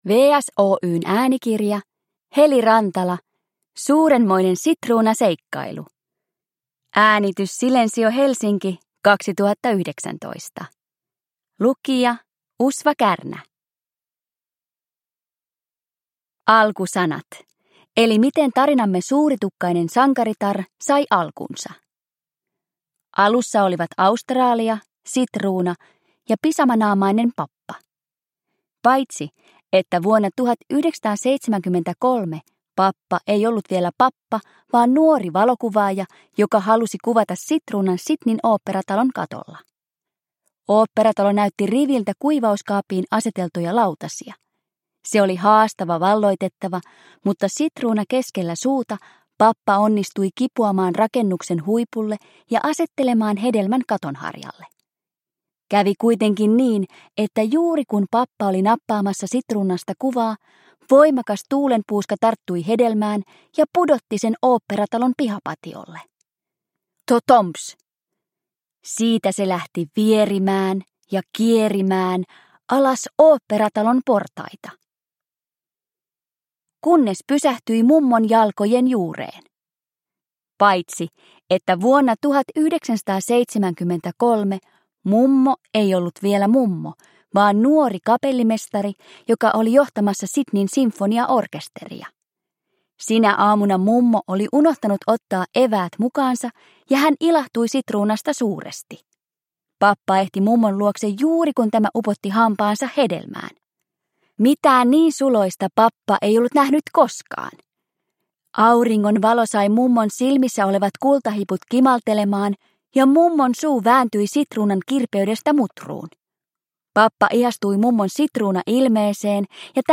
Suurenmoinen sitruunaseikkailu (ljudbok) av Heli Rantala